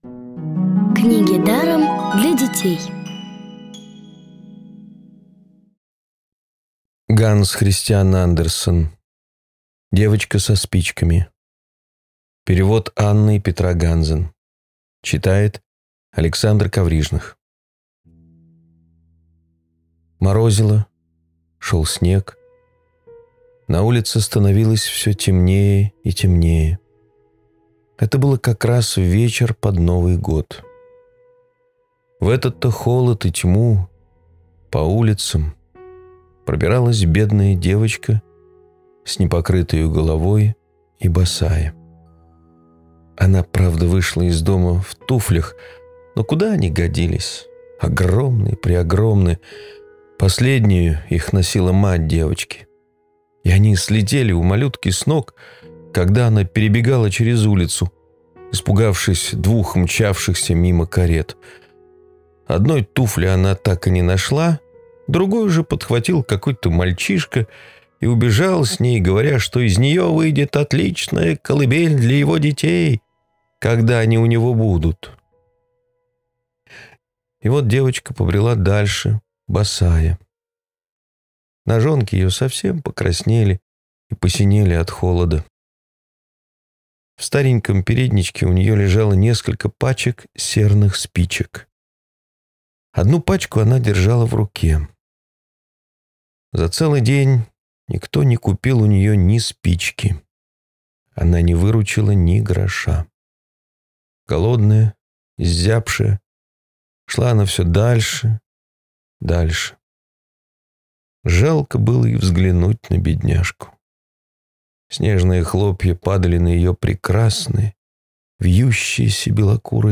Главная Аудиокниги Для детей
Аудиокниги онлайн – слушайте «Девочку со спичками» в профессиональной озвучке и с качественным звуком.